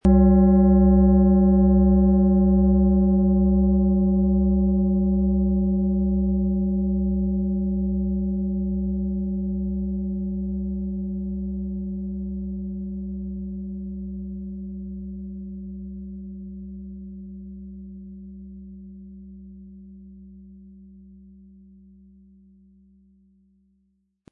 Lieferung mit richtigem Schlägel, er lässt die Klangschale harmonisch und wohltuend schwingen.
HerstellungIn Handarbeit getrieben
MaterialBronze